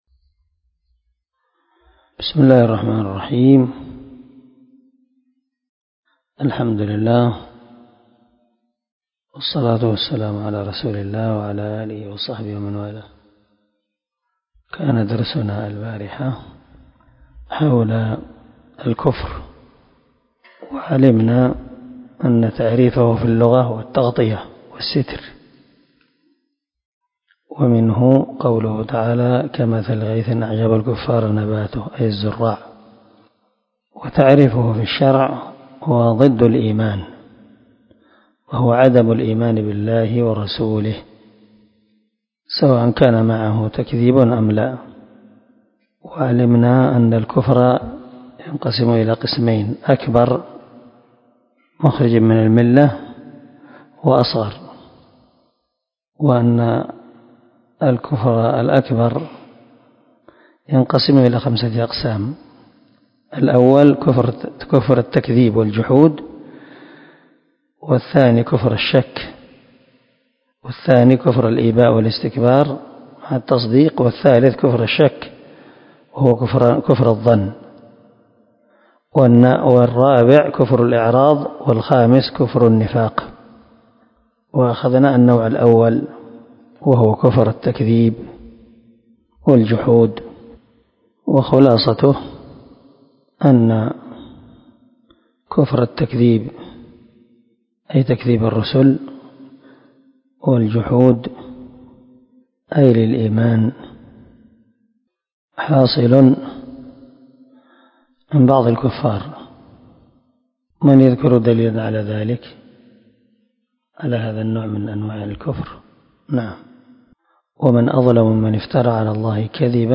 🔊الدرس 32 النوع الثاني من أنواع الكفر الذي يخرج من الملة كفر الاباء والاستكبار